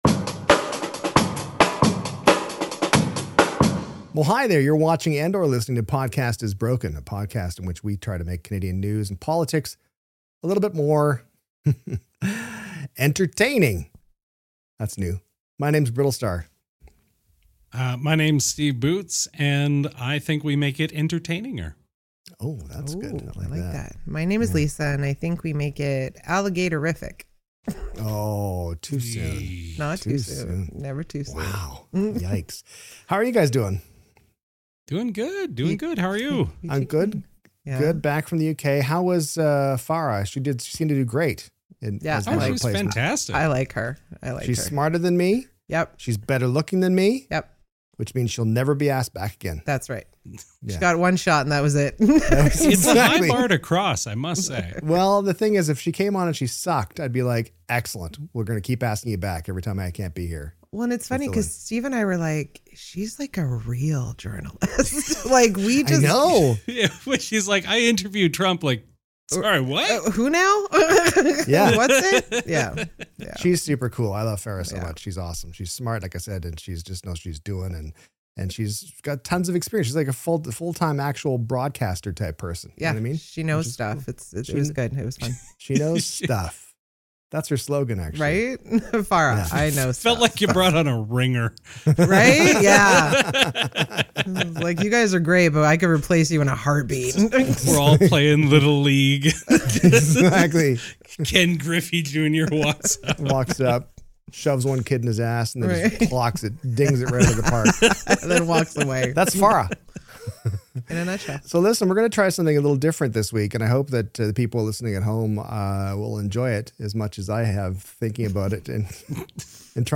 A goat causes highway mayhem, processed meats get cancelled, and the Pope revives an old vacation spot. Also: AI scams, boa constrictors, dishwasher disasters, and why nobody in the UK seems to own a dryer. It’s part quiz show, part news roundup, part fever dream.